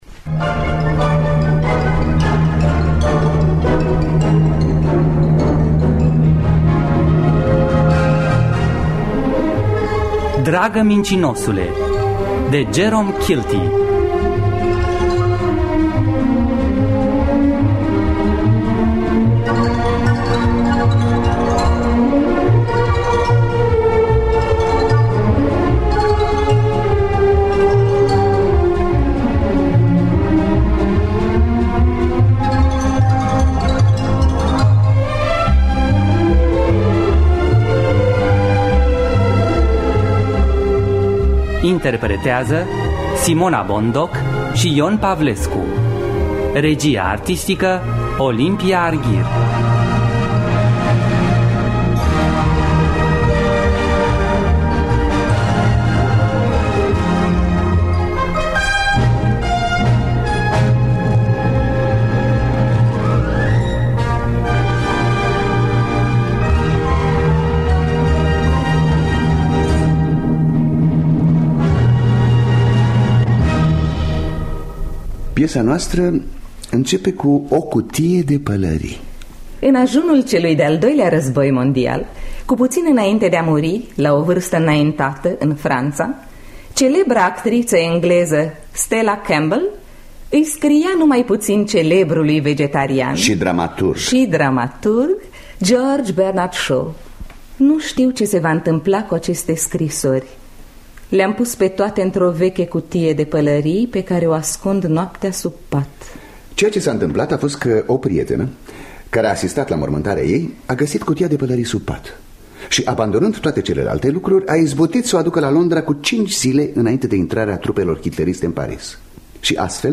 Adaptarea radiofonică de Simona Bondoc.